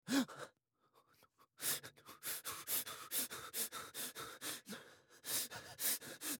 Звуки запыхавшегося человека
Испуганное частое дыхание мужчины (звук)